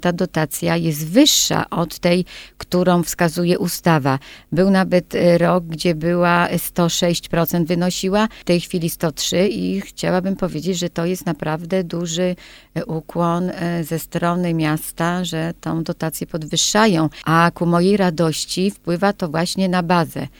Mówiła Anna Łukaszewska, Kujawsko - Pomorska Kurator Oświaty.